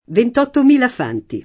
venti [v%nti] num.